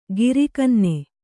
♪ giri kanne